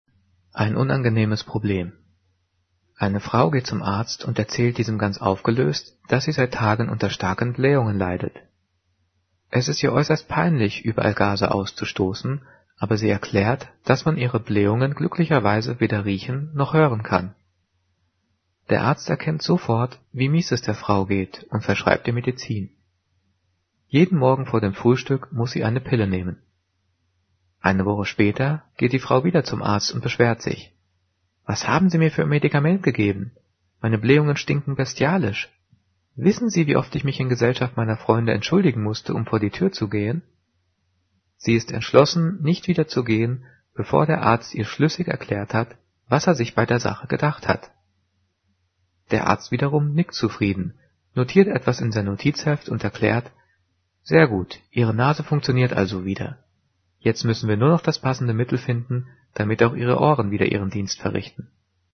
Diktat: "Ein unangenehmes Problem" - 5./6. Klasse - s-Laute
Gelesen:
gelesen-ein-unangenehmes-problem.mp3